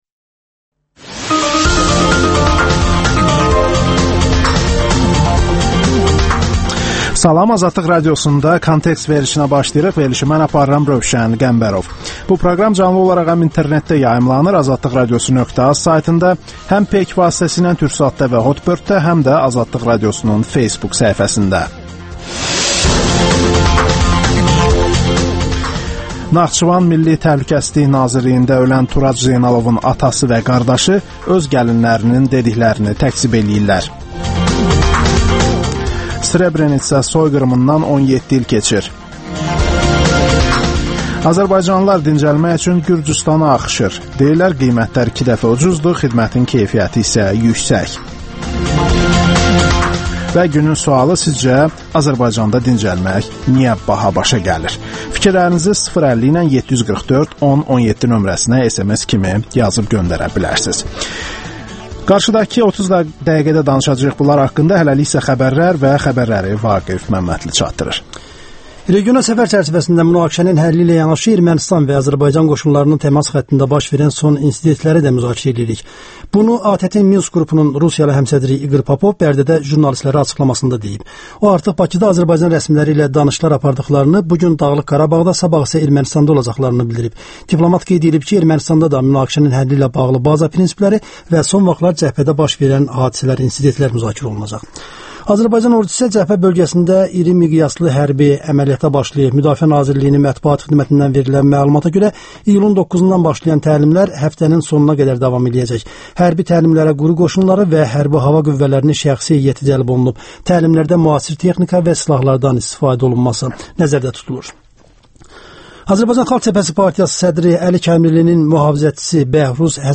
Müsahibələr, hadisələrin müzakirəsi, təhlillər